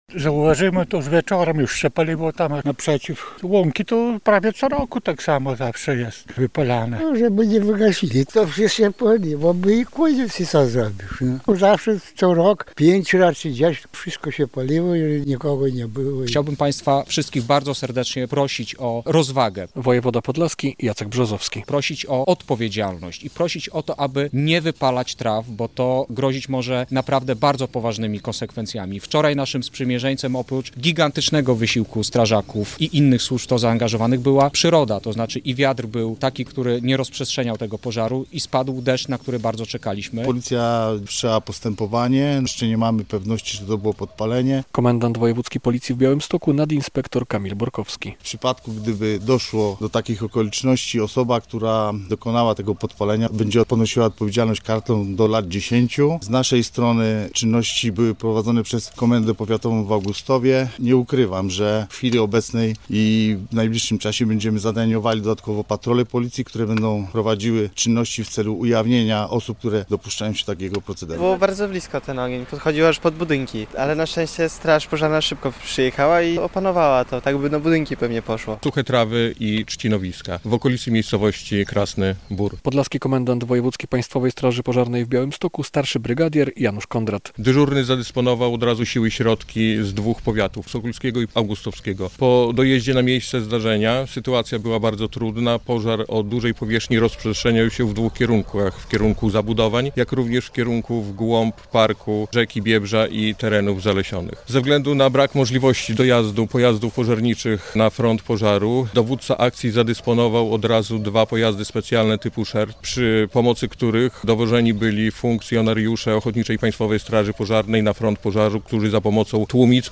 Po pożarze w Biebrzańskim Parku Narodowym - relacja